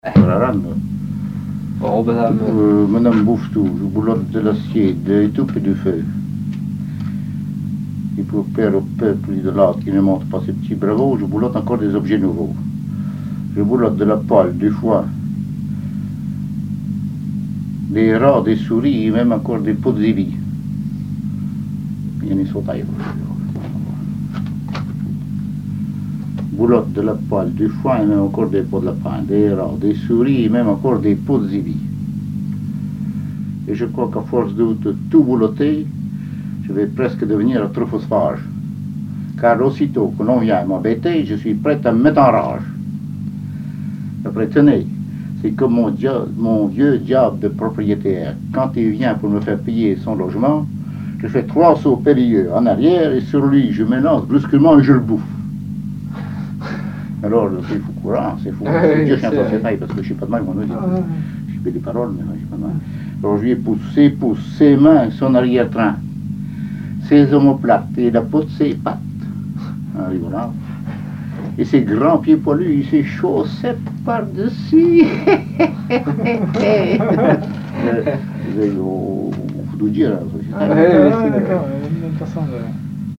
Saint-Hilaire-de-Riez
Genre récit